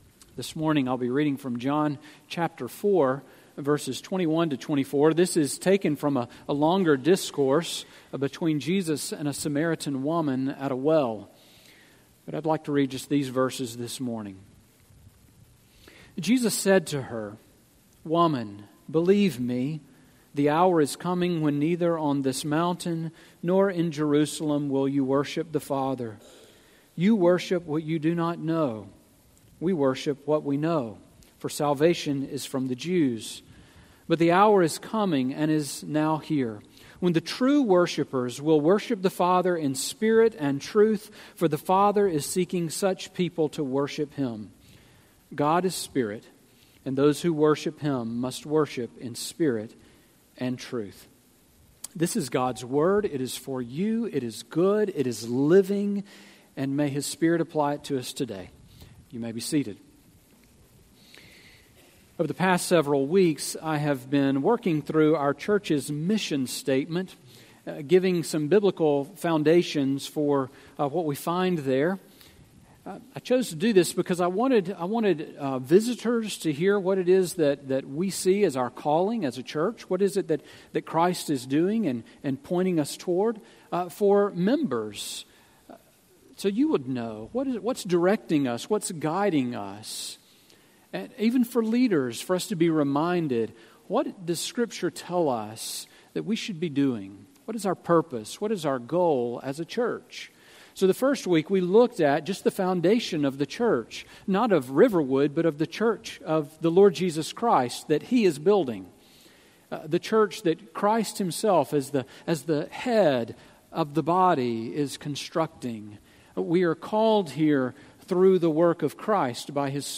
Sermon Audio from Sunday
Sermon on Exodus 20:4-6 from September 18